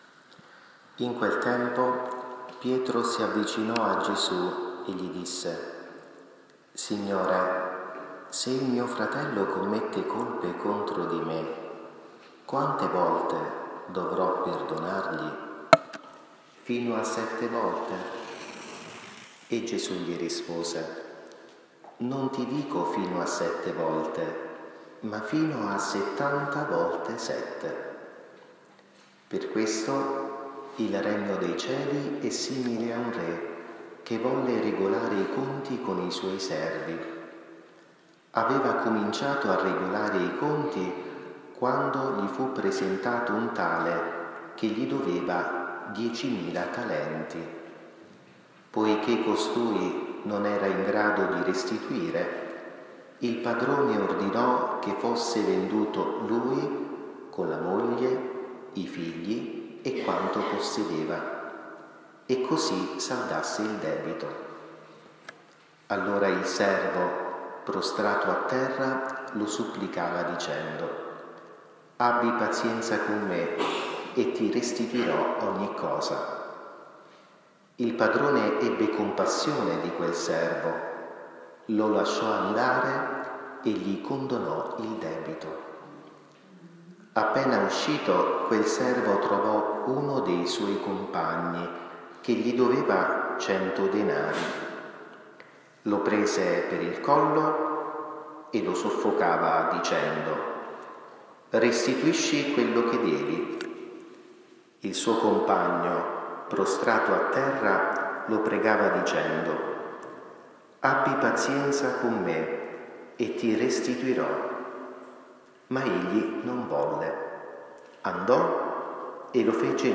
XXIV DOMENICA DEL TEMPO ORDINARIO – 17 settembre 2023